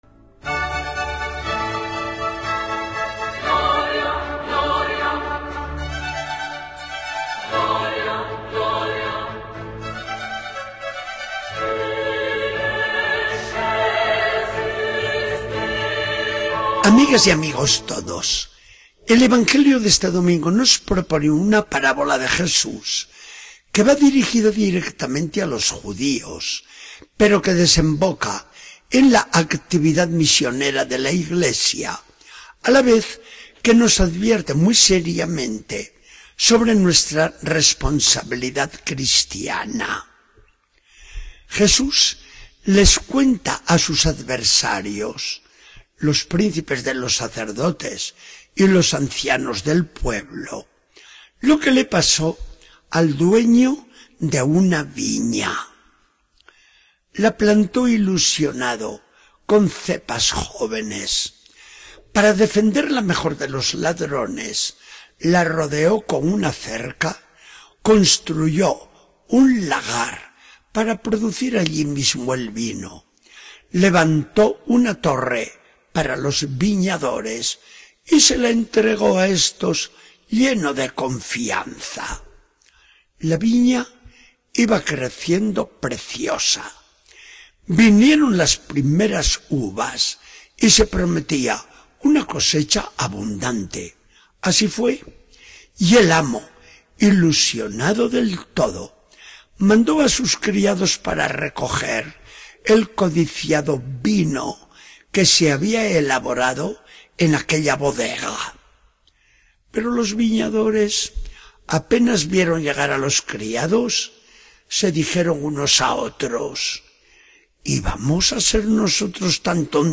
Charla del día 5 de octubre de 2014. Del Evangelio según San Mateo 21, 33-46.